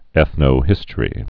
(ĕthnō-hĭstə-rē)